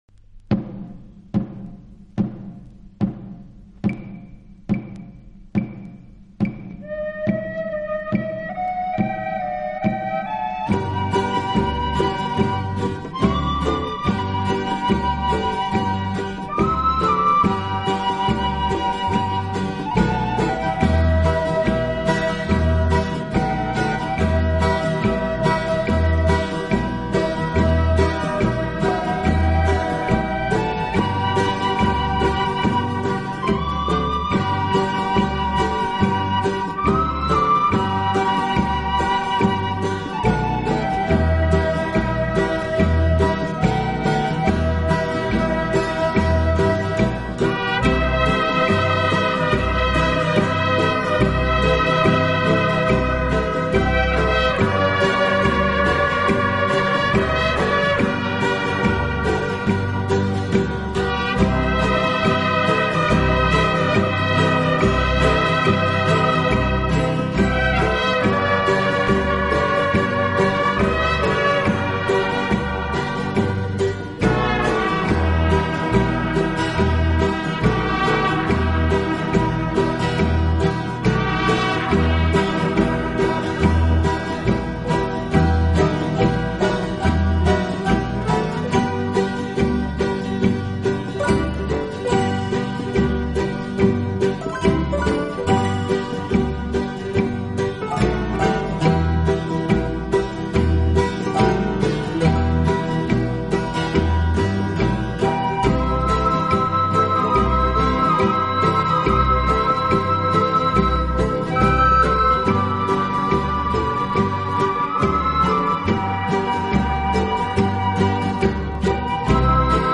以擅长演奏拉丁美洲音乐而著称。
乐器的演奏，具有拉美音乐独特的韵味。